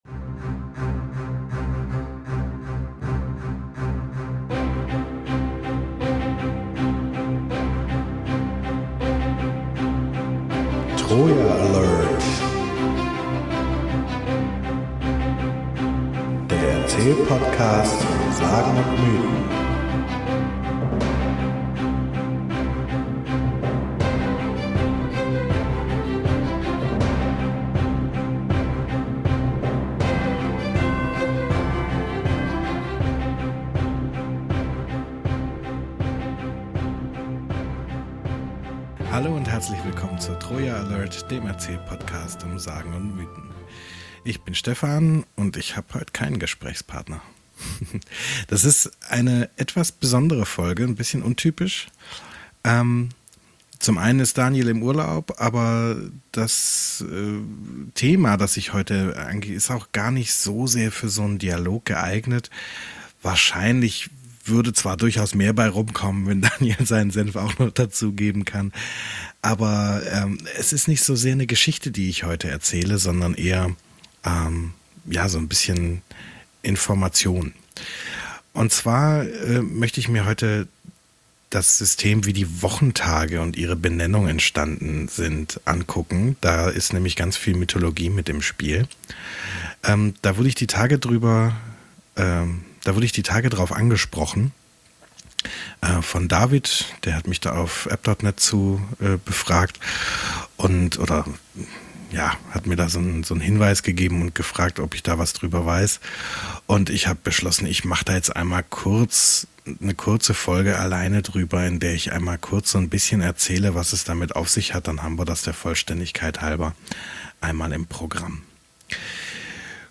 Die Namen der Wochentage Ein kurzes Solo zur Benennung der Wochentage in europäischen Sprachen und den zugehörigen griechisch-römischen Göttern bzw. den zugehörigen den germanischen Göttern.